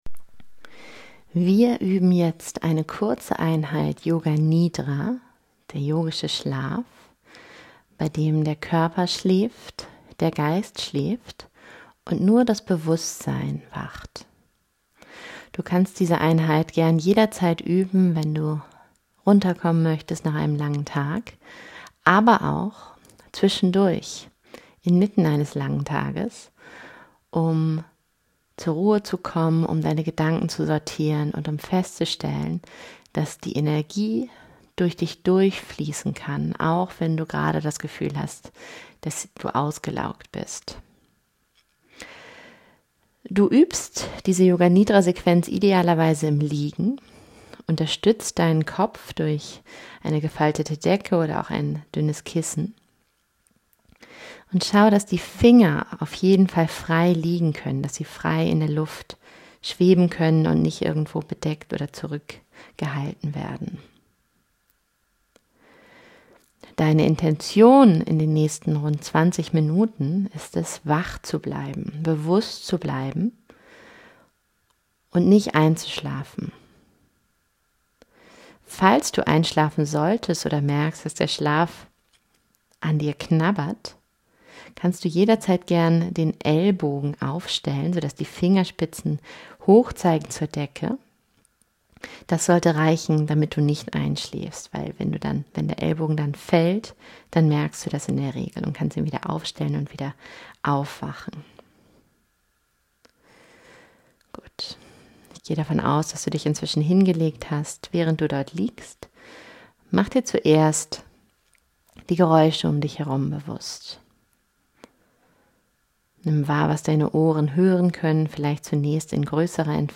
4.5-Yoga-Nidra
4.5-Yoga-Nidra.mp3